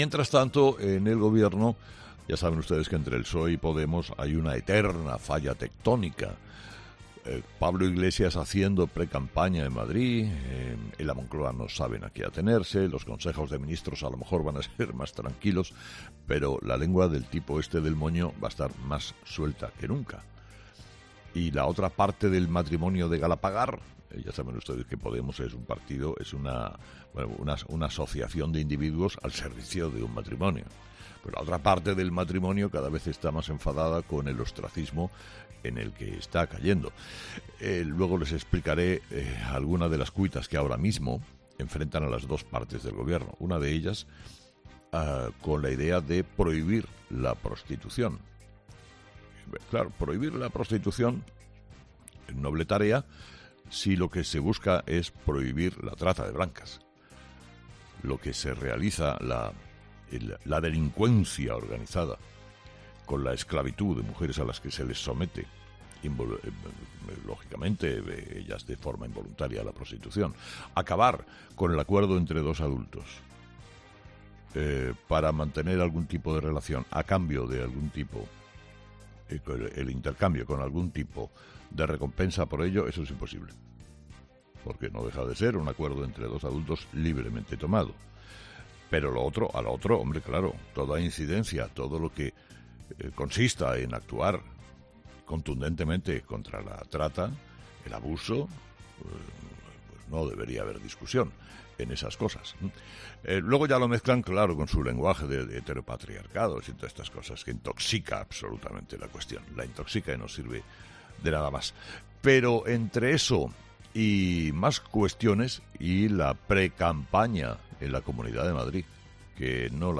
El director y presentador de 'Herrera en COPE', Carlos Herrera, ha comenzado el programa de este lunes analizando las principales claves de la actualidad, que principalmente pasan por la situación de estancamiento de la pandemia, con nueve regiones que ha informado de repuntes en las últimas jornadas.